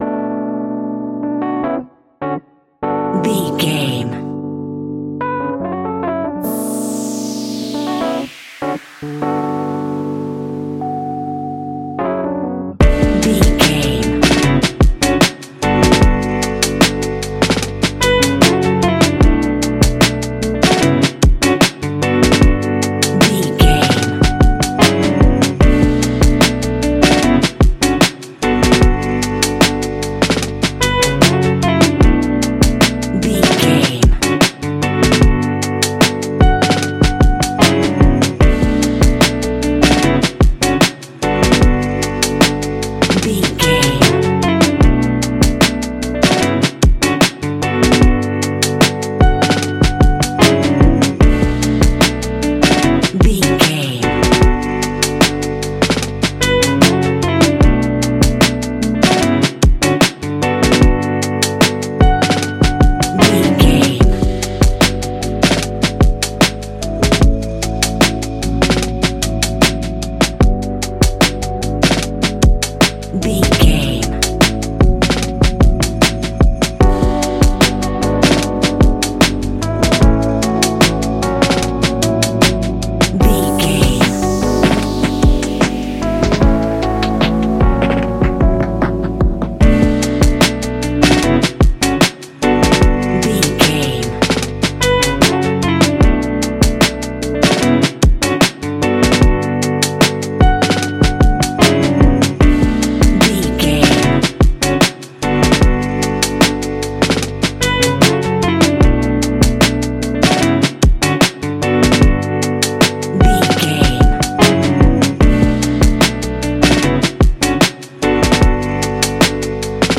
Ionian/Major
D♭
laid back
Lounge
sparse
new age
chilled electronica
ambient
atmospheric
morphing